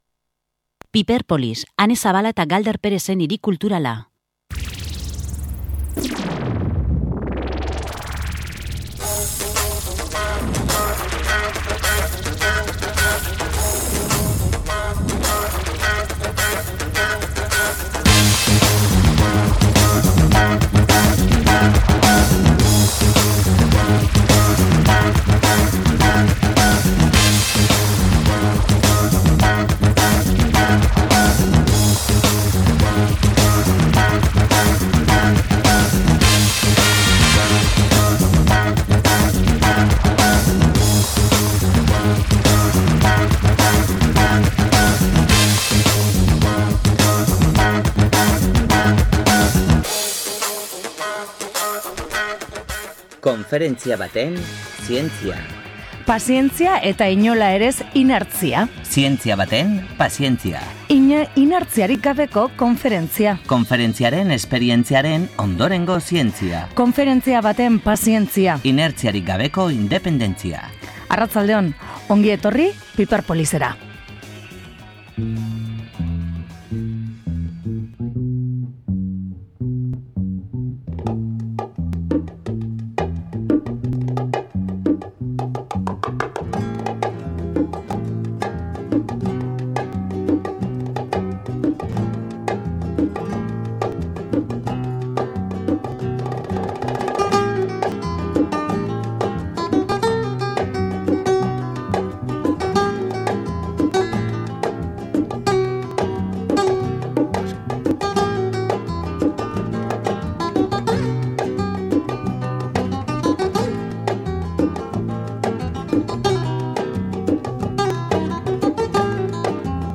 Aurkezpenean Asier Altuna eta Mailaen Lujanbio eta Andoni Egañaren hitzak jaso genituen, gaurko saioan entzun ditugu.